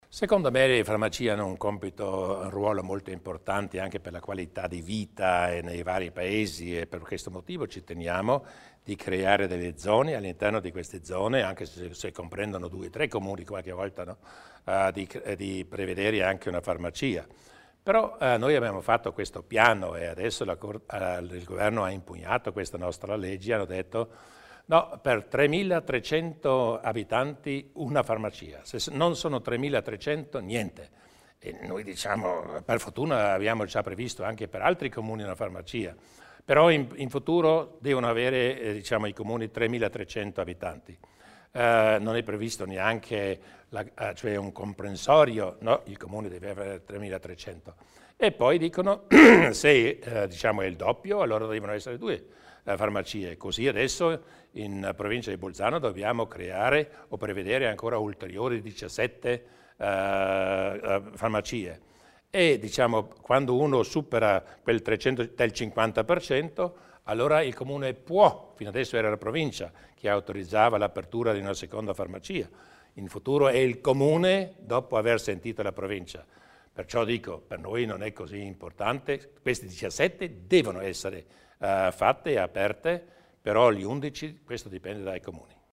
Il Presidente Durnwalder spiega le novità per le farmacie in provincia di Bolzano